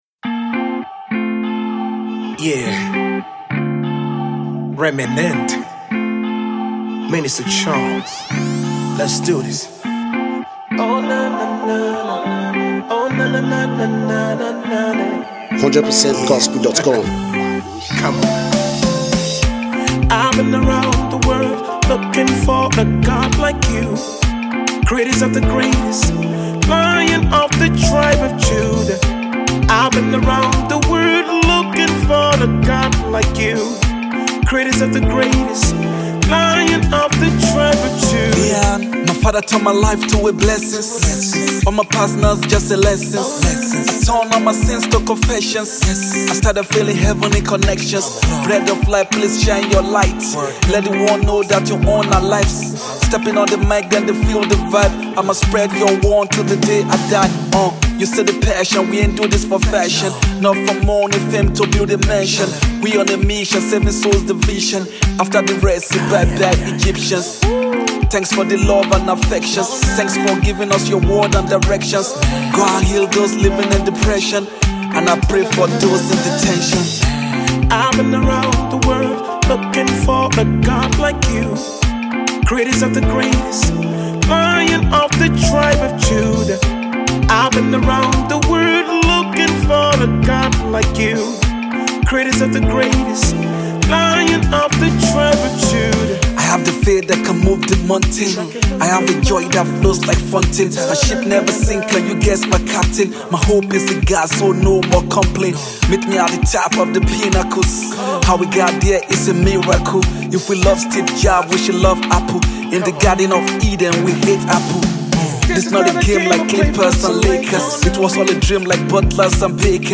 Christian Hip Hop act